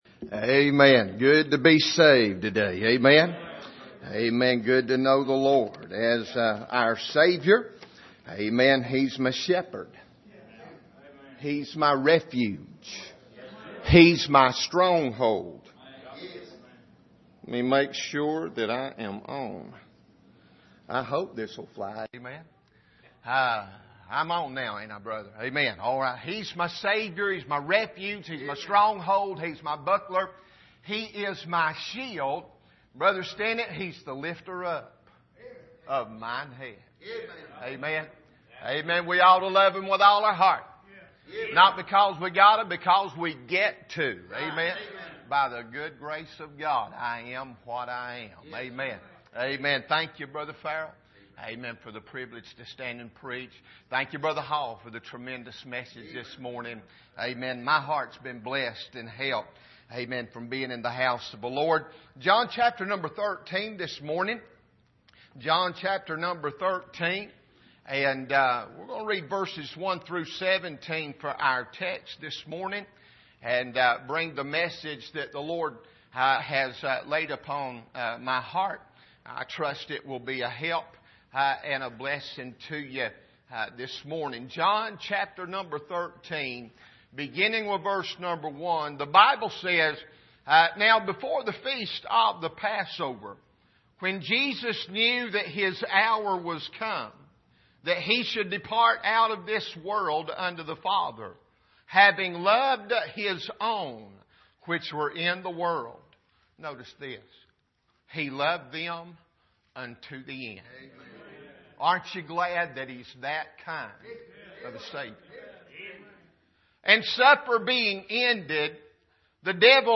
Preacher
2016 Missions Conference Passage: John 13:1-17 Service: Missions Conference How Are We At Washing Feet?